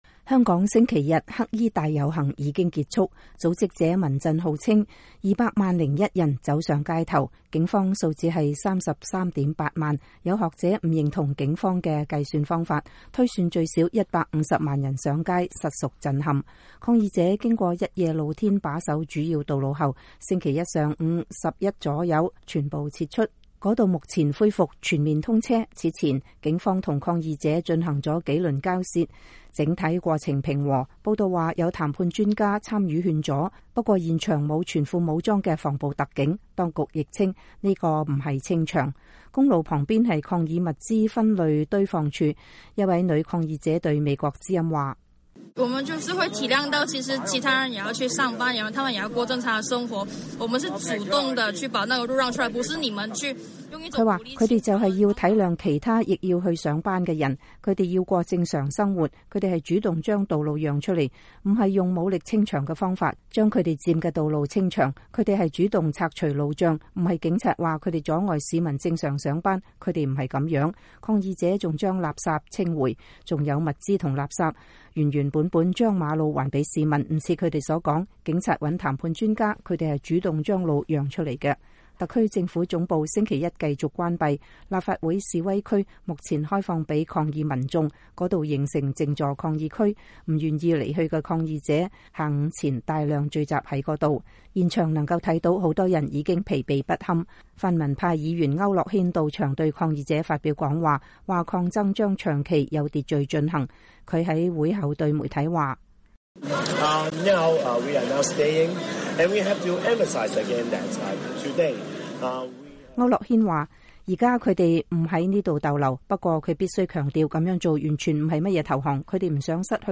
2019-06-17 美國之音視頻新聞: 香港“黑衣大遊行”落幕 抗爭轉常態繼續推動訴求